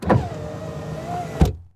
windowpart2.wav